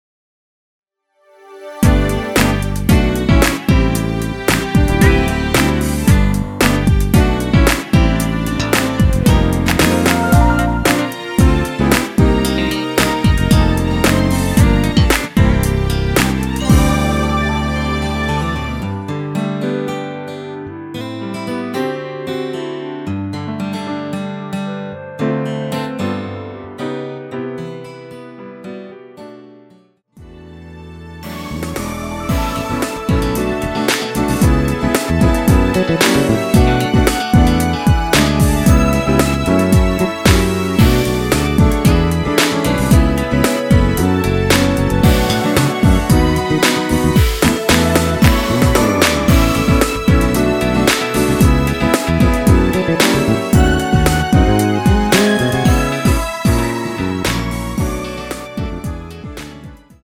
원키에서(-1)내린 멜로디 포함된 MR 입니다.(미리듣기 참조)
멜로디 MR이라고 합니다.
앞부분30초, 뒷부분30초씩 편집해서 올려 드리고 있습니다.
중간에 음이 끈어지고 다시 나오는 이유는